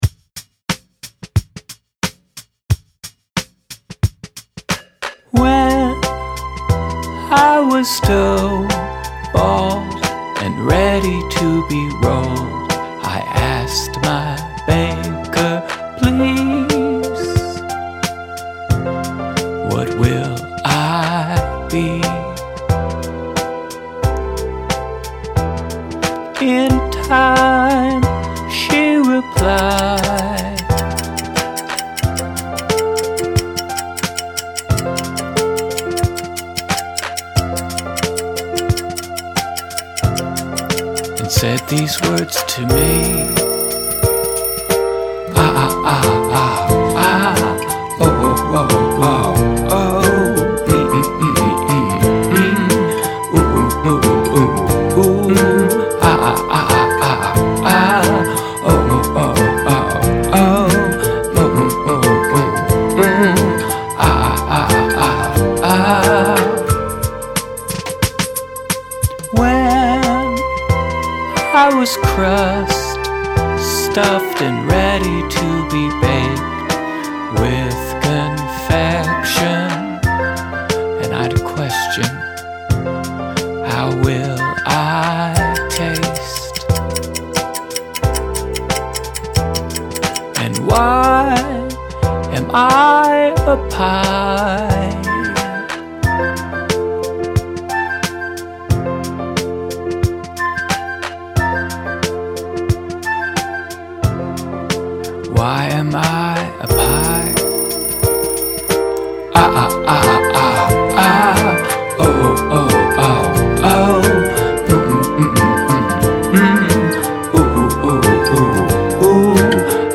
verse, chorus, verse, chorus, bridge, chorus